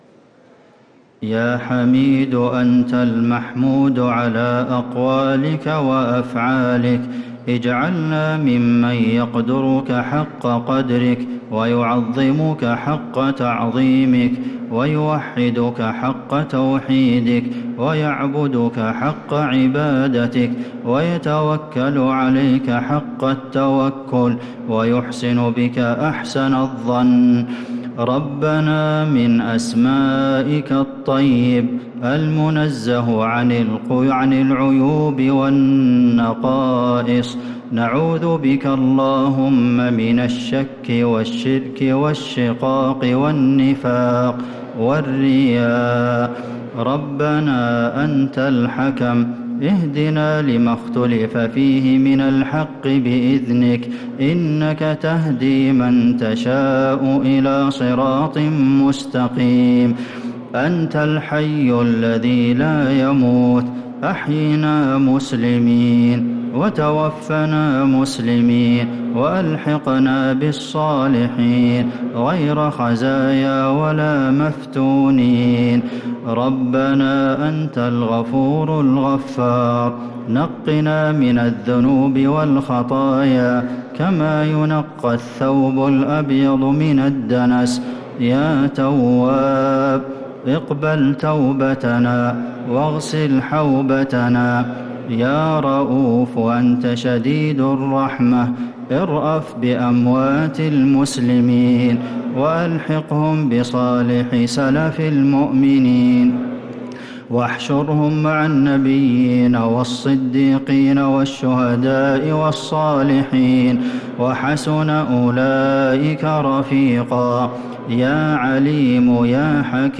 دعاء القنوت ليلة 16 رمضان 1446هـ | Dua 16th night Ramadan 1446H > تراويح الحرم النبوي عام 1446 🕌 > التراويح - تلاوات الحرمين